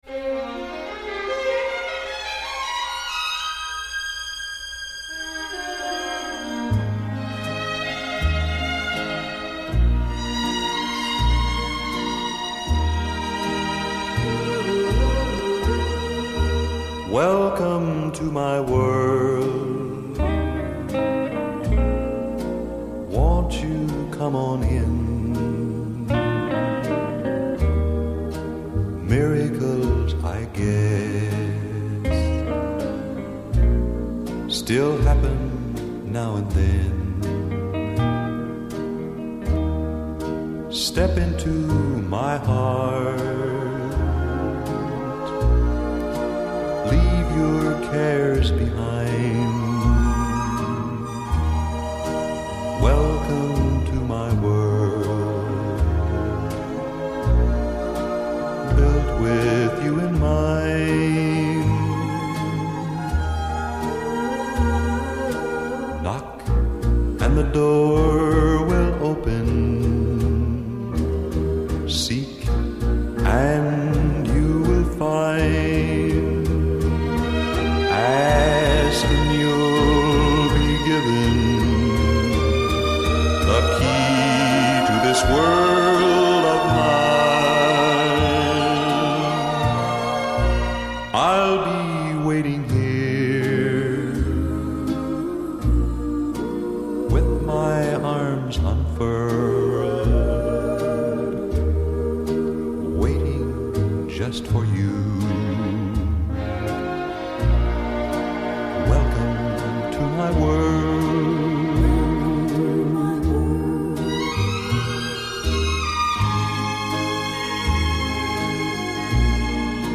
обожаю такие старые темы, с оркестровыми вставками